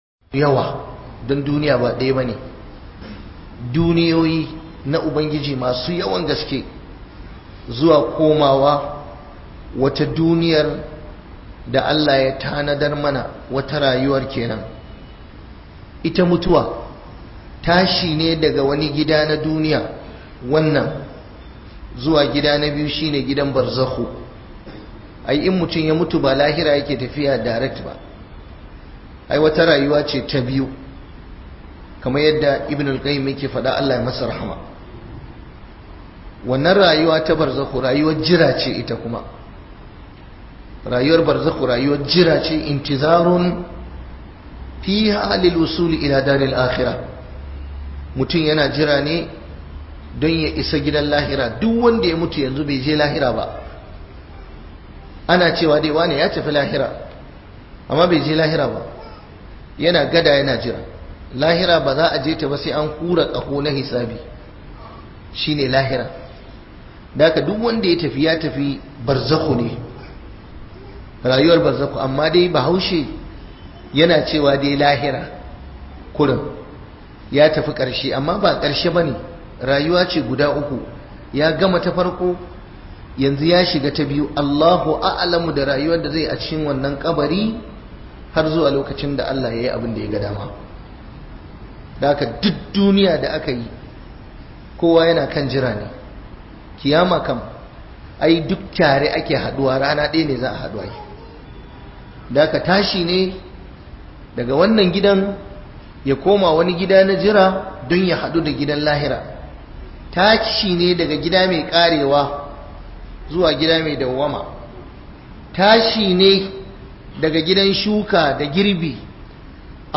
MUTUWA - Huduba